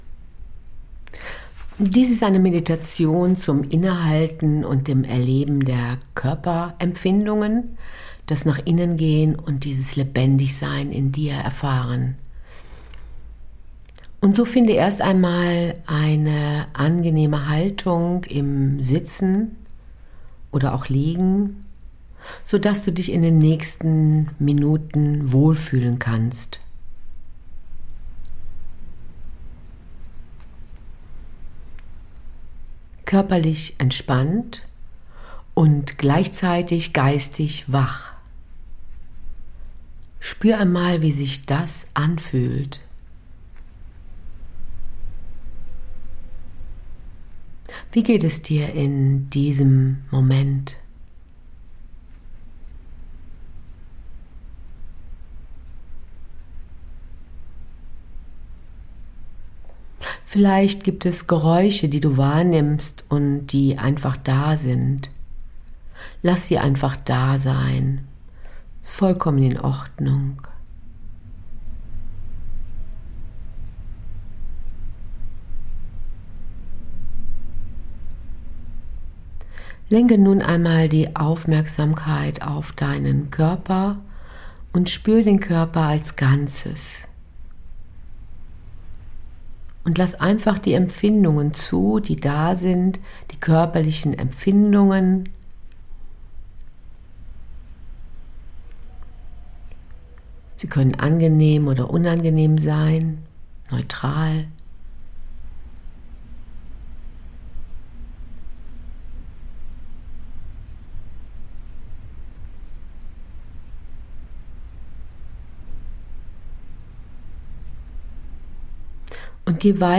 Ein kurzer wirkungsvoller Body-Scan